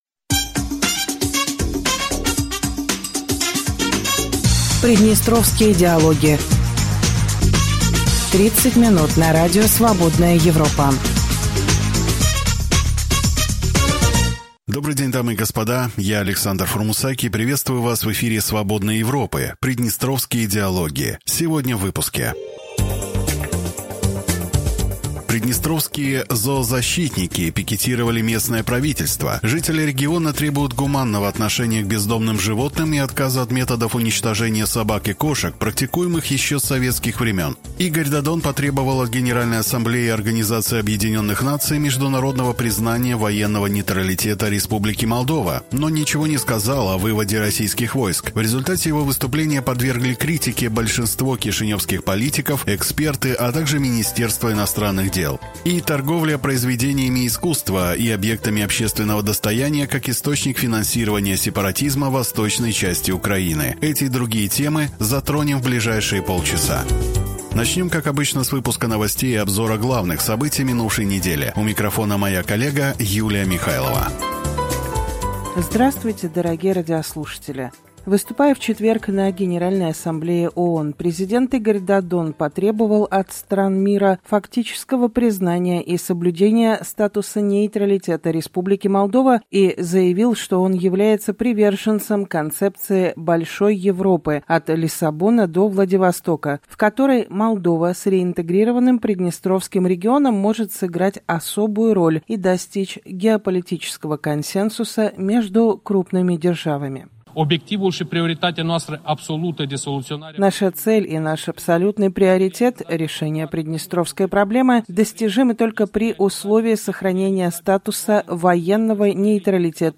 Добрый день, дорогие радиослушатели!